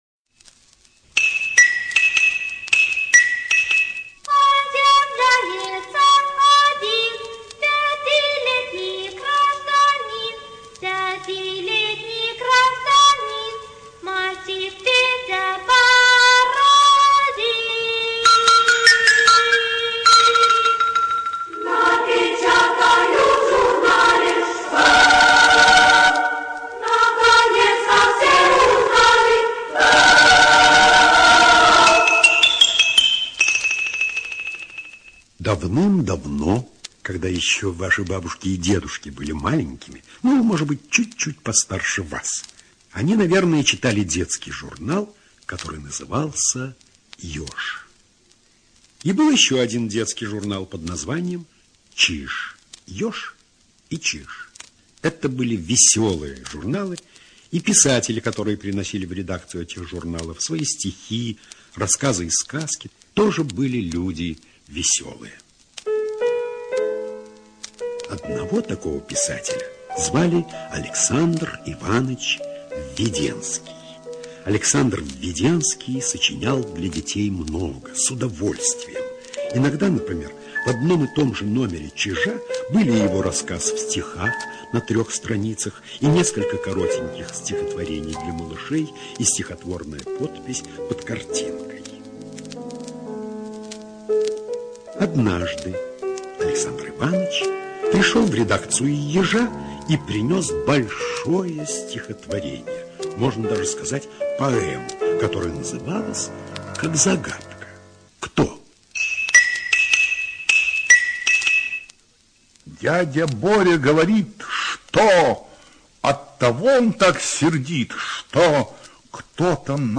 ЧитаютПлятт Р., Гердт З.